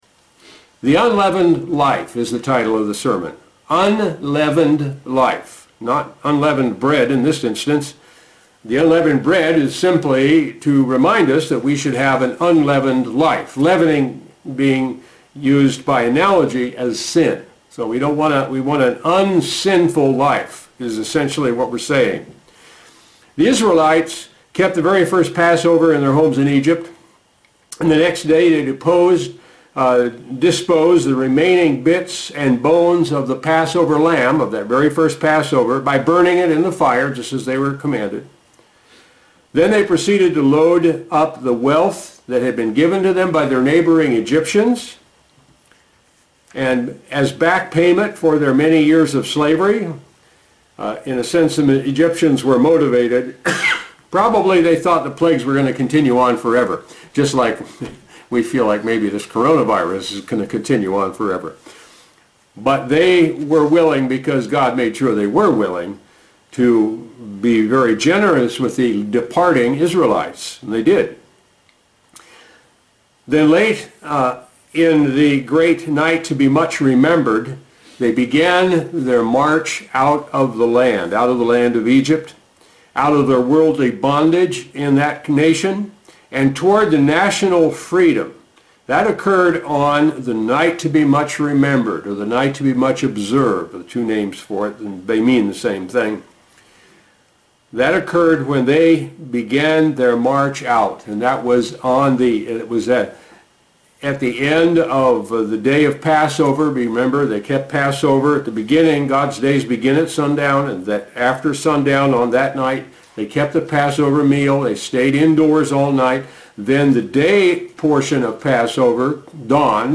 Sermons
Given in North Canton, OH